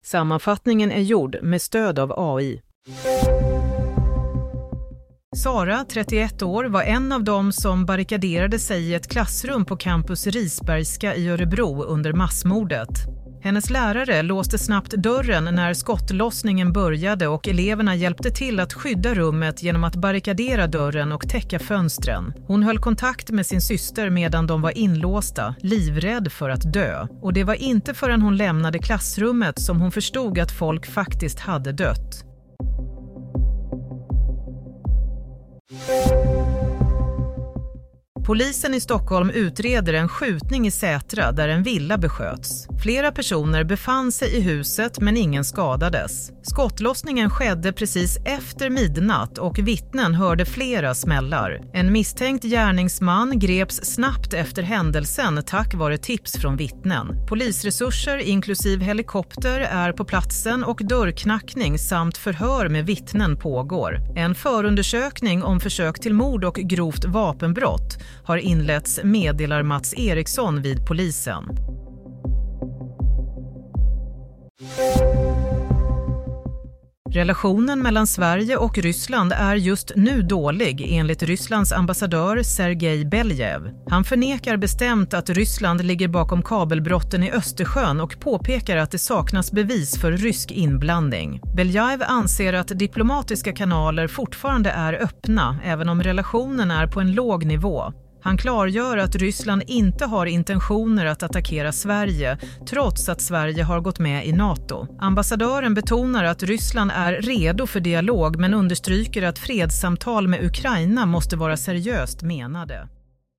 Nyhetssammanfattning - 6 februari 07:00